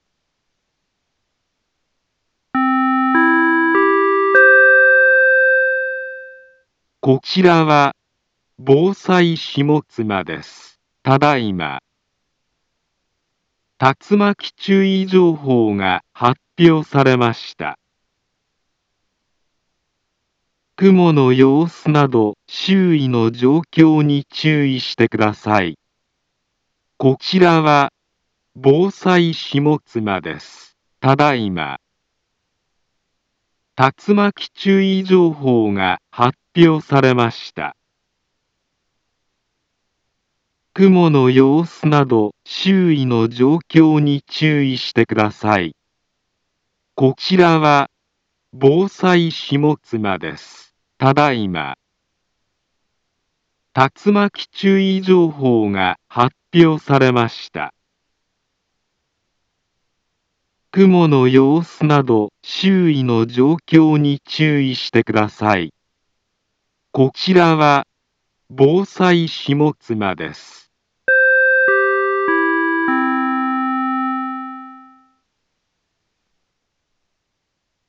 Back Home Ｊアラート情報 音声放送 再生 災害情報 カテゴリ：J-ALERT 登録日時：2025-09-05 15:59:03 インフォメーション：茨城県南部は、竜巻などの激しい突風が発生しやすい気象状況になっています。